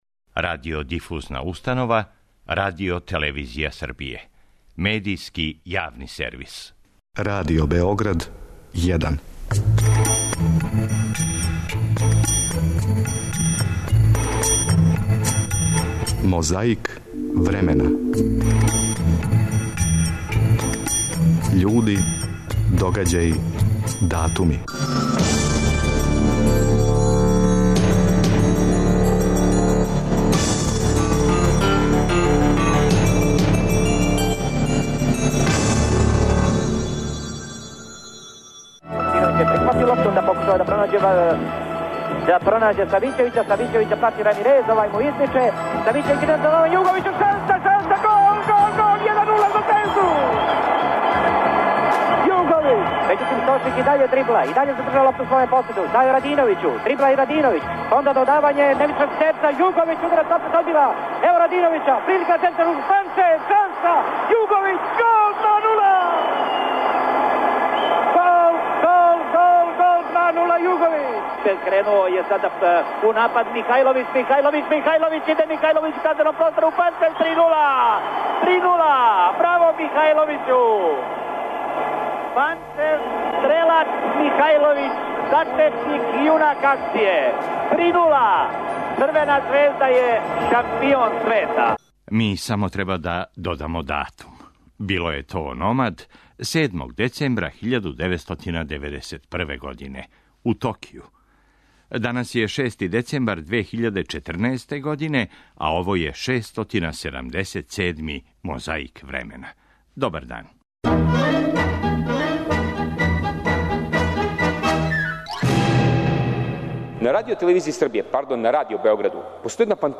То је једини пут до сада да је тим из Србије, бивше Југославије али и из целе источне Европе постао светски број један у фудбалу. Чућете како је, тим поводом, емисија Радио Београда 'Недељом у 10', почела сутрадан.